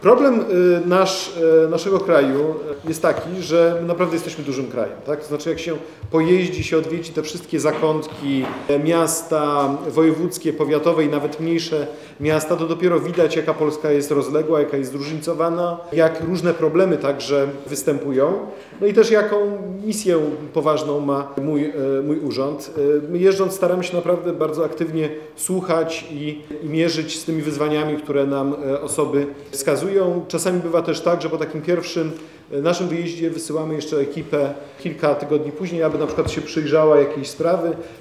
Adam Bodnar spotkał się w środę (16.05) z mieszkańcami w tamtejszym ratuszu.
-O stanie praw człowieka w Polsce nie można dowiadywać się wyłącznie z pism przysyłanych do Biura Rzecznika Praw Obywatelskich – powiedział na wstępie Adam Bodnar